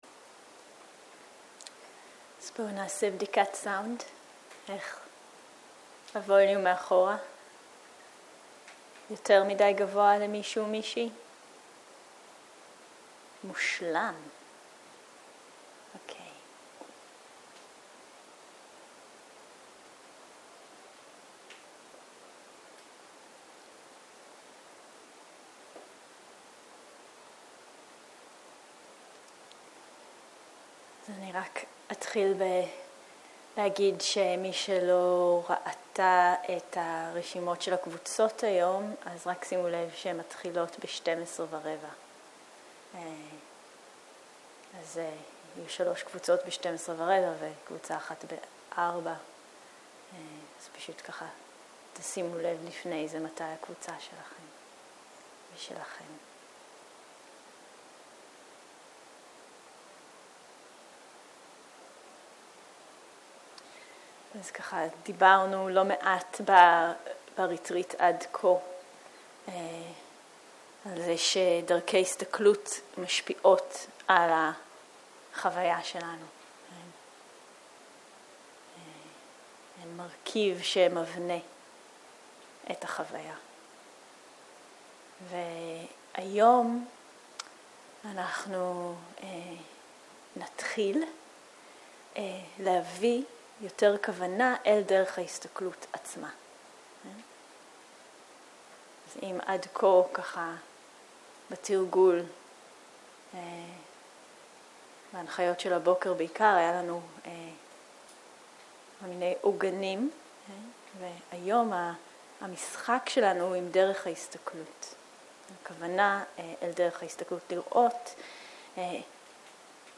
בוקר - הנחיות מדיטציה + מדיטציה מונחית
סוג ההקלטה: מדיטציה מונחית